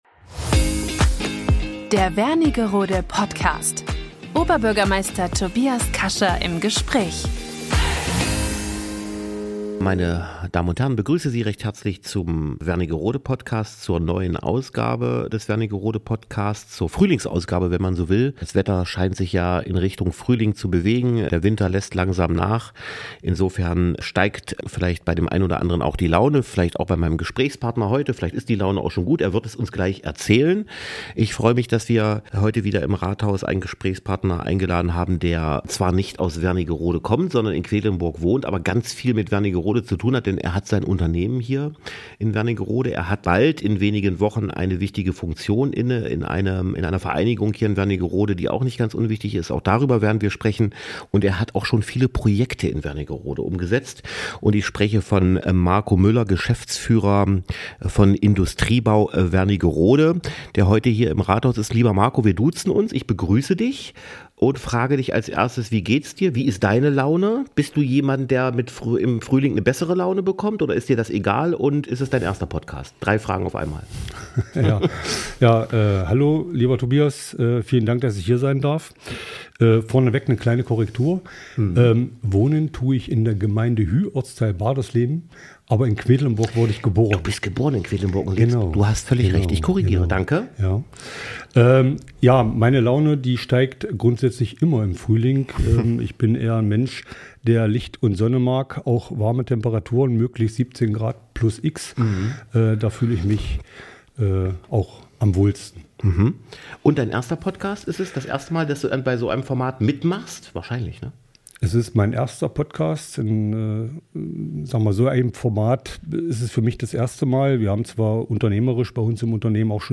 Wernigerode Podcast #53 - Oberbürgermeister Tobias Kascha im Gespräch